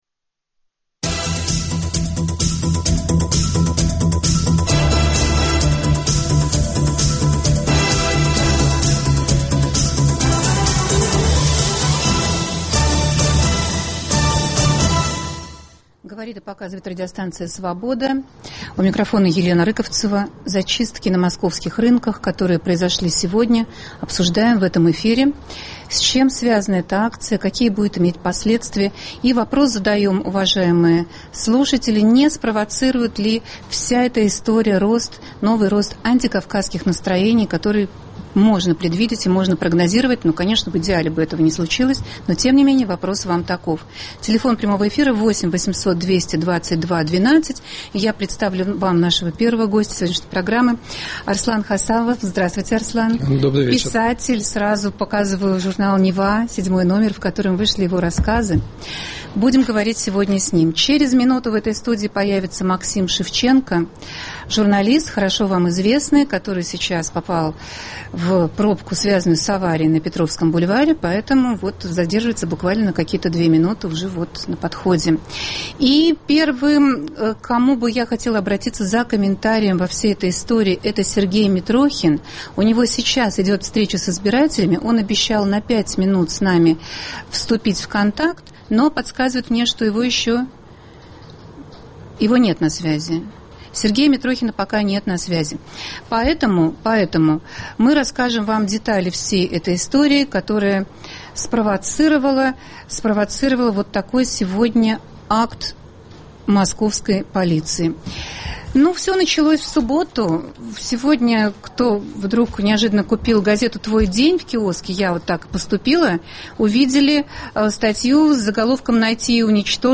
С комментарием выступит политик Сергей Митрохин.